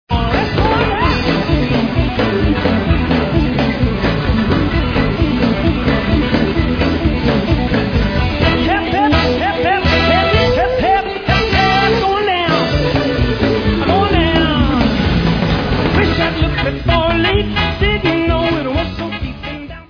Live concert
sledovat novinky v kategorii Rock